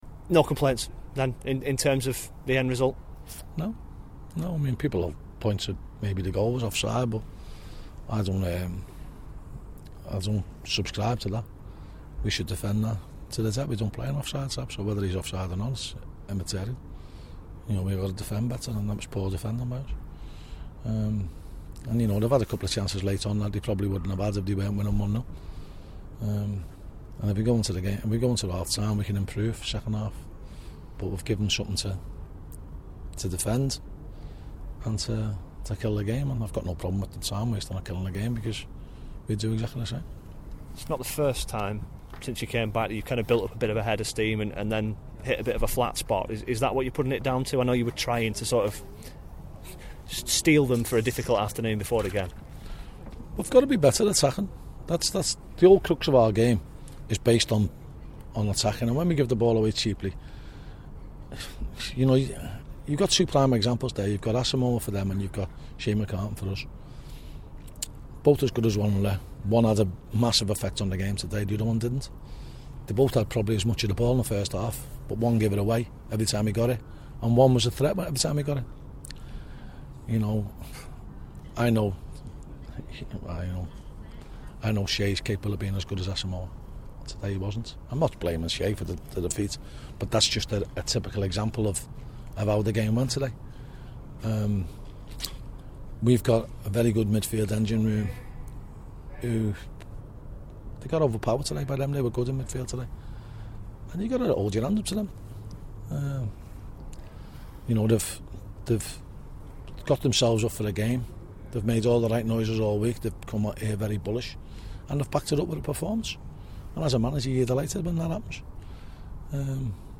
speaks to the club's official website after the 1-0 defeat at Carlisle United